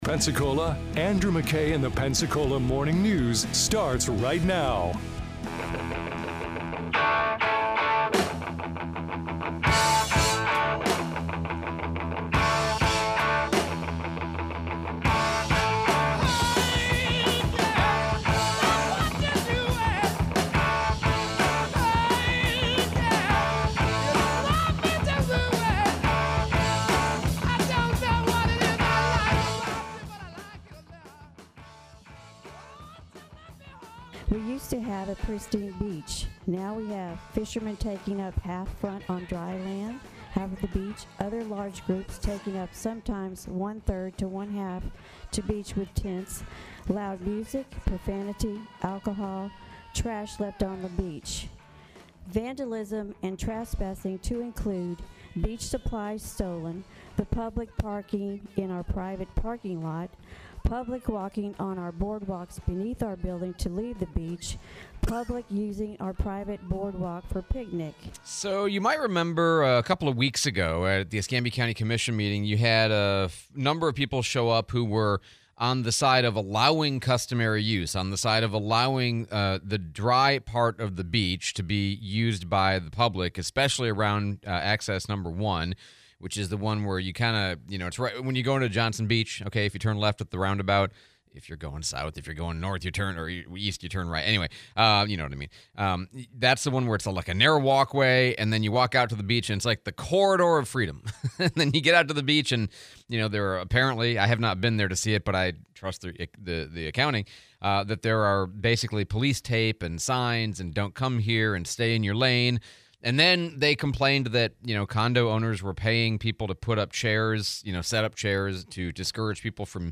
Private vs Public beachfront property, Mayor Reeves interview